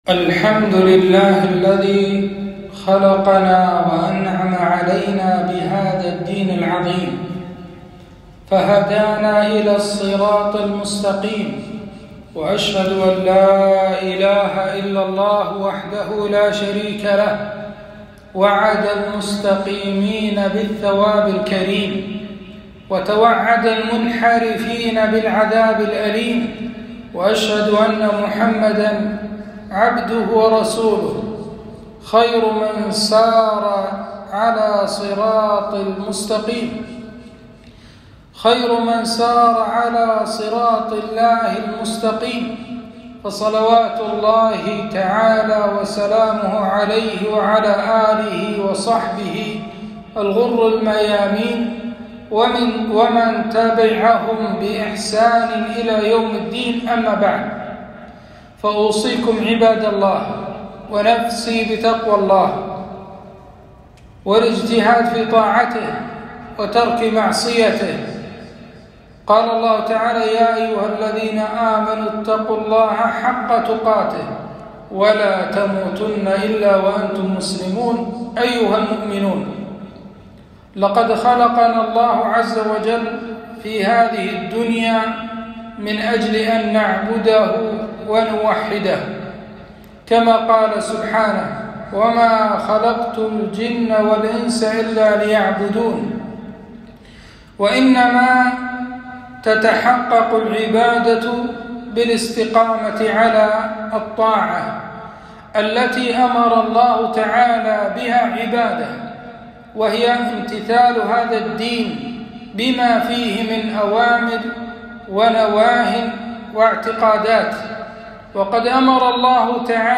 خطبة - انحراف الشباب أسبابه ووسائل علاجه